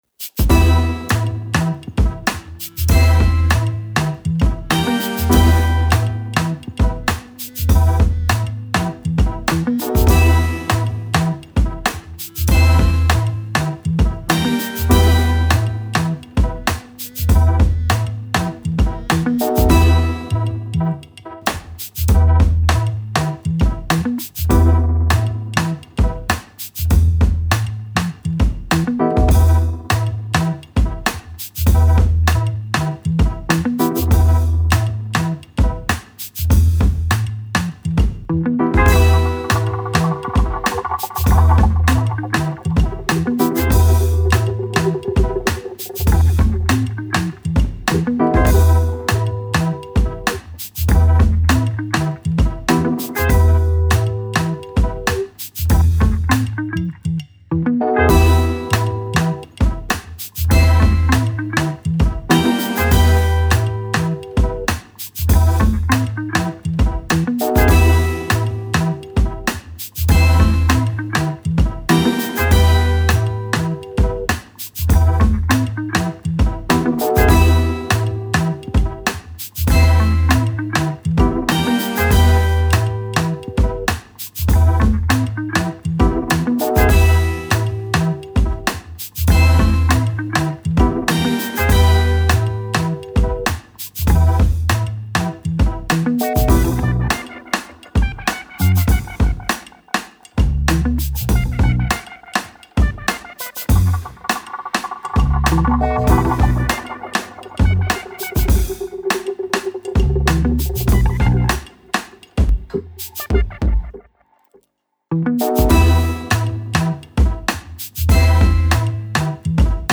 Electronic beds and groovy beat.